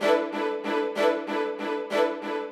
GS_Viols_95-E1.wav